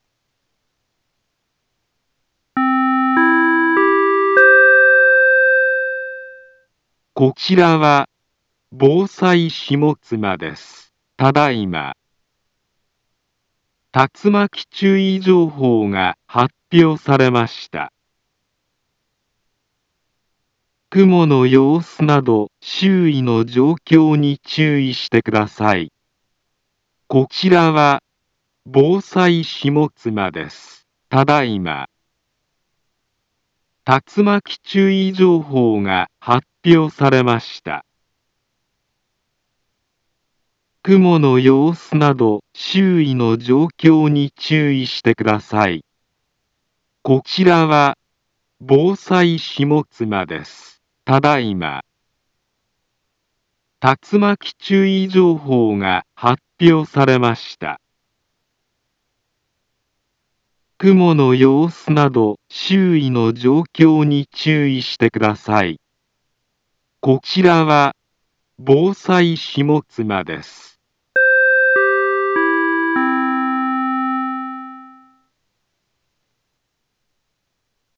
Back Home Ｊアラート情報 音声放送 再生 災害情報 カテゴリ：J-ALERT 登録日時：2023-06-28 20:15:17 インフォメーション：茨城県南部は、竜巻などの激しい突風が発生しやすい気象状況になっています。